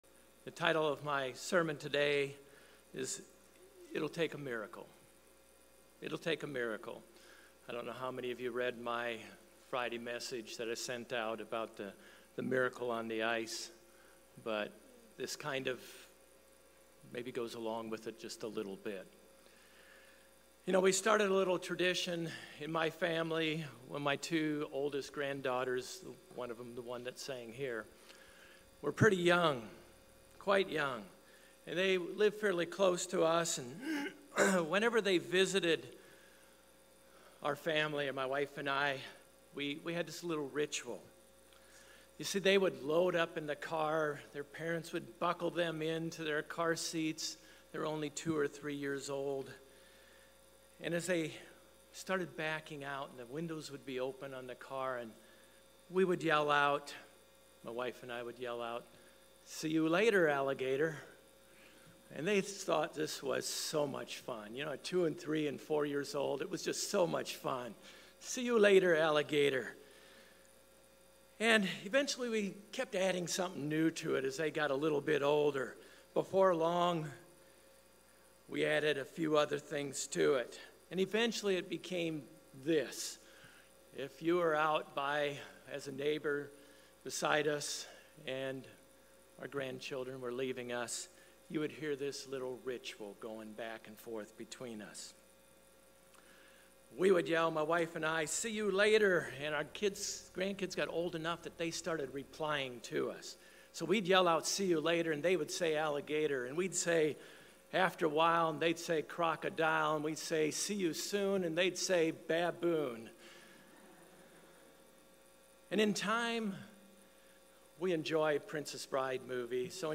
Sermons
Given in Orlando, FL Jacksonville, FL